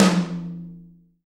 • Big Room Tom One Shot F Key 13.wav
Royality free tom drum tuned to the F note. Loudest frequency: 1151Hz
big-room-tom-one-shot-f-key-13-UDb.wav